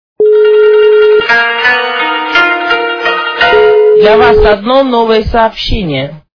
» Звуки » звуки для СМС » Японское СМС - Для Вас одно новое сообщение
При прослушивании Японское СМС - Для Вас одно новое сообщение качество понижено и присутствуют гудки.